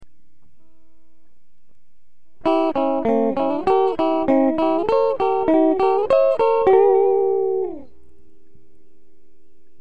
Add guitar 2 playing the inversion..